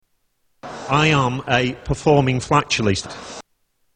A performing flatulist
Tags: Comedians Mr Methane Fart Fart Music Paul Oldfield